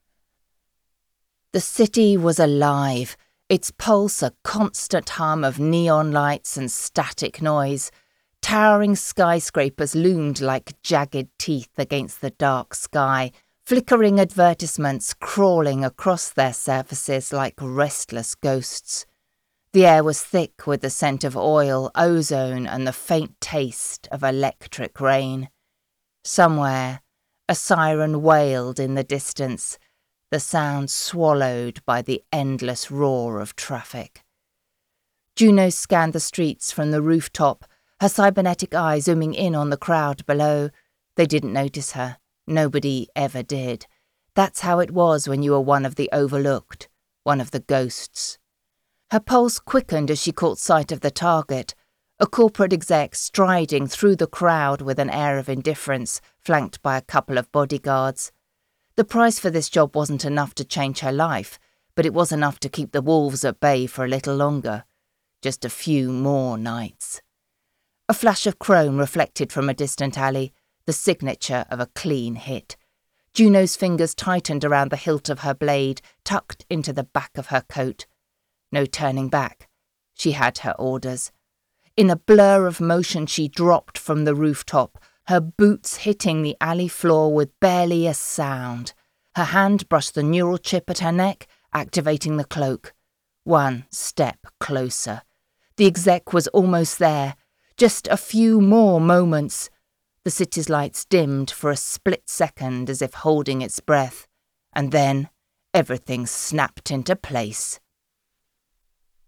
Female
I offer an RP British voice with excellent diction and an expressive, nuanced delivery.
My voice is warm, clear, and naturally engaging.
Audiobooks